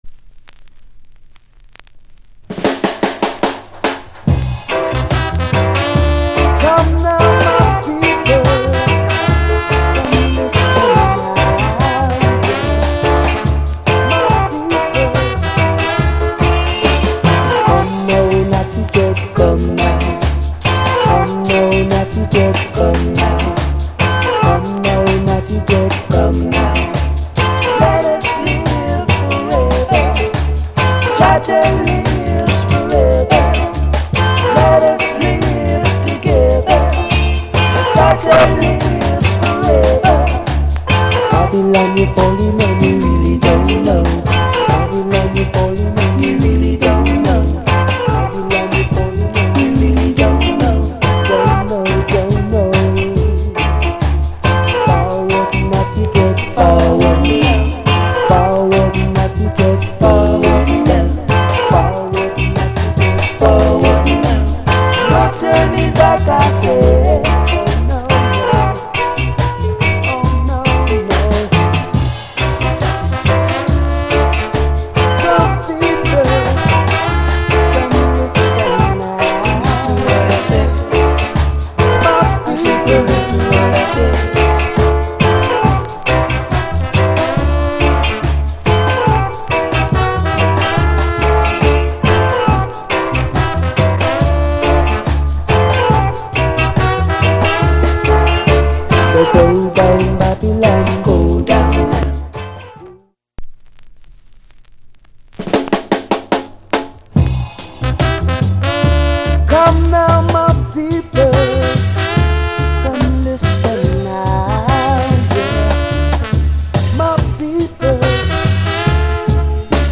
** 途中一瞬音が乱れますので、試聴でご確認ください。